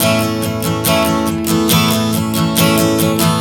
Strum 140 A 03.wav